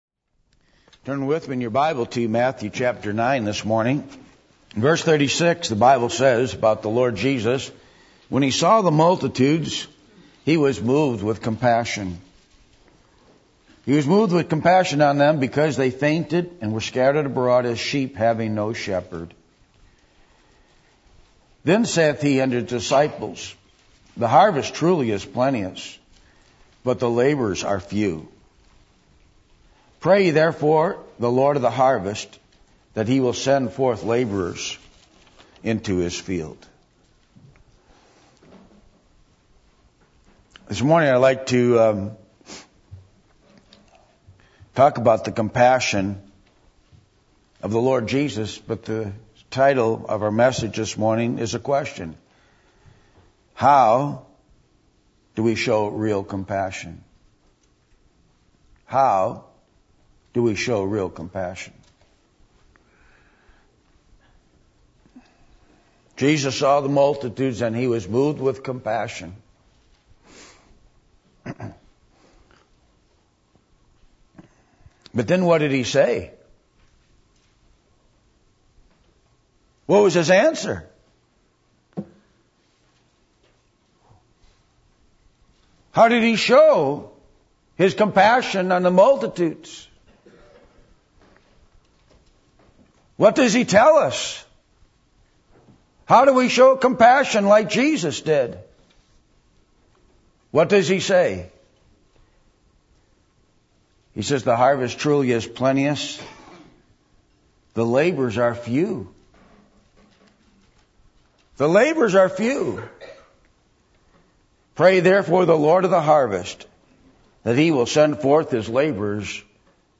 Matthew 9:36-38 Service Type: Sunday Morning %todo_render% « Are You Being Setup?